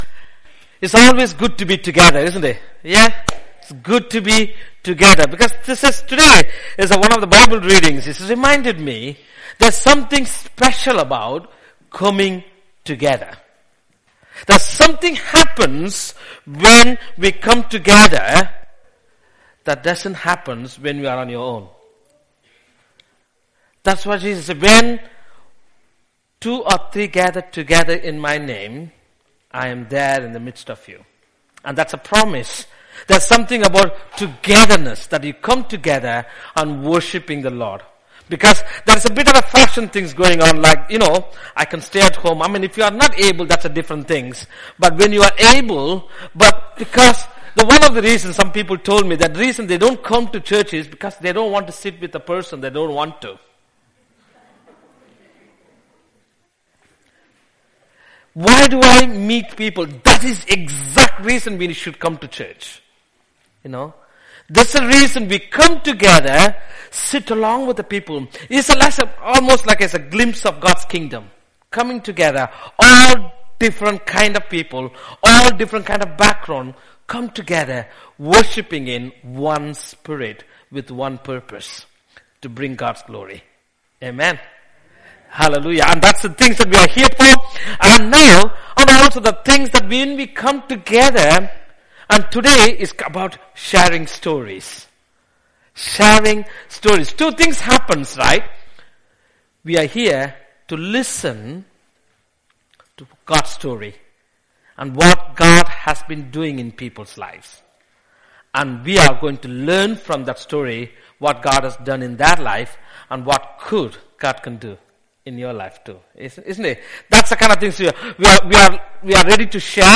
The interview, short sketch and talk are available as an audio file.
03-02-interview-drama-talk.mp3